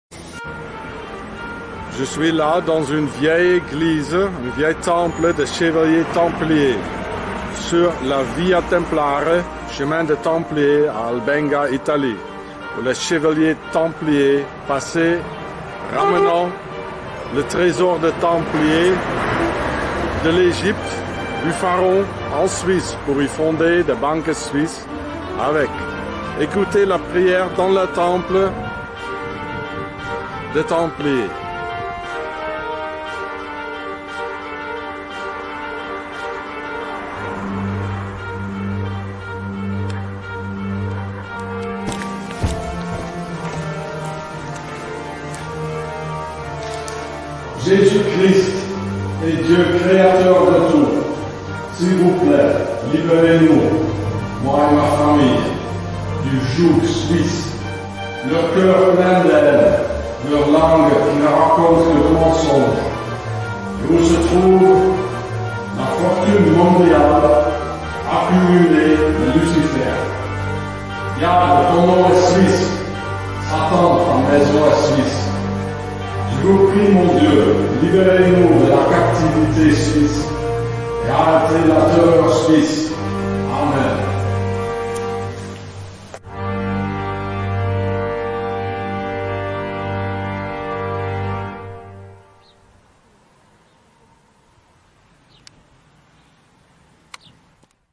Liberez moi et ma Famille du Joug Suisse (Priere dans Eglise des Chevaliers Templiers)